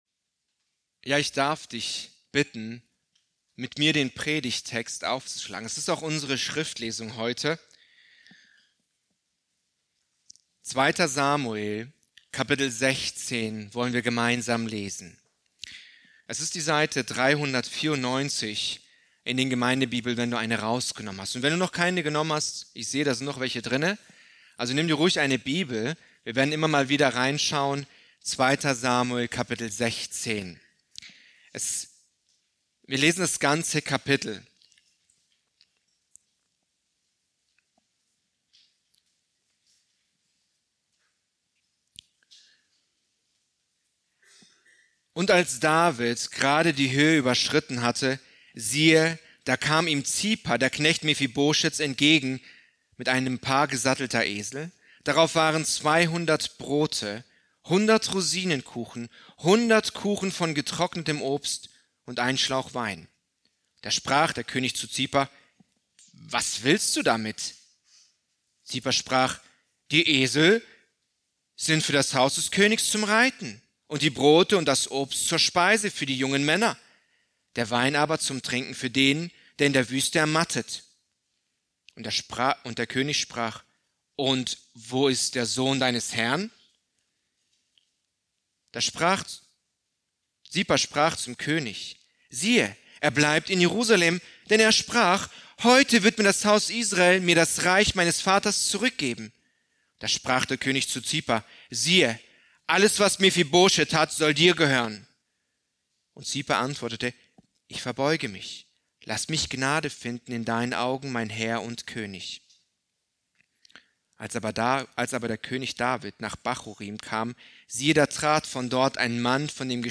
Predigt aus der Serie: "2. Samuel"